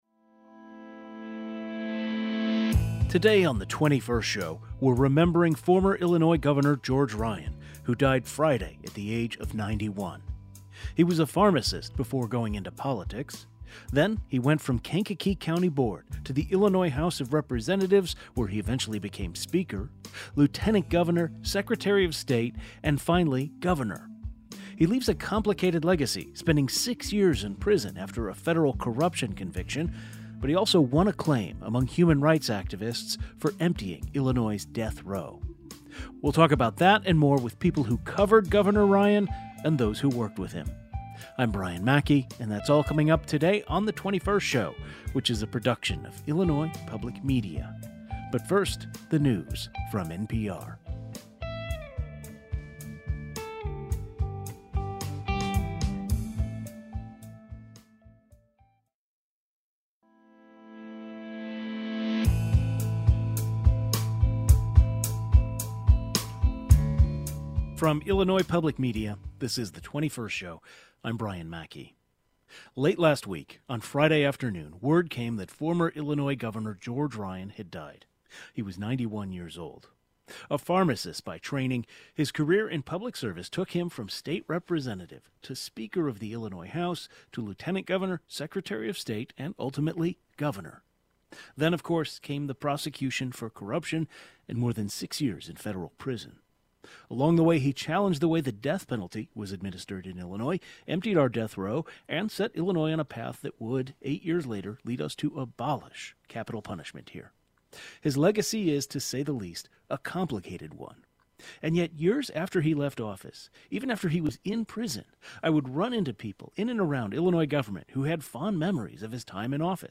Journalists who covered Ryan and two individuals who worked with him share their memories of him.